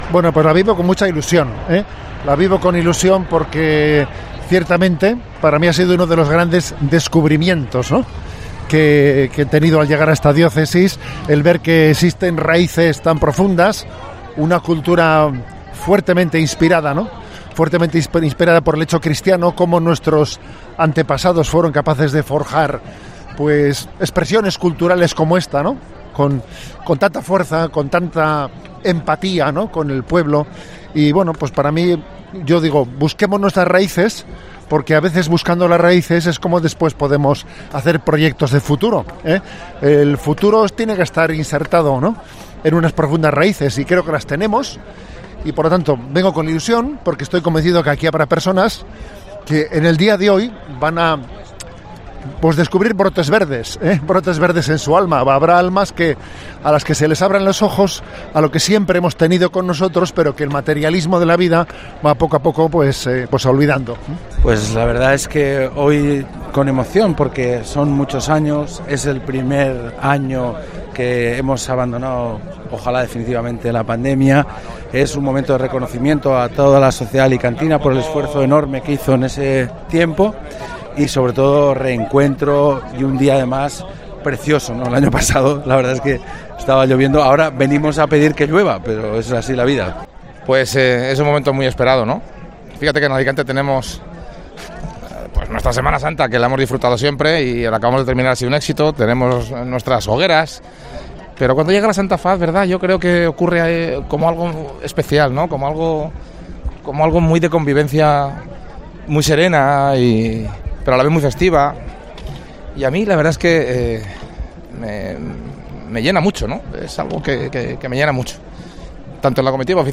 Así ha sido el paso de la comitiva oficial de Santa Faz por la paraeta de COPE Alicante